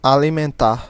Ääntäminen
IPA : /fuːd/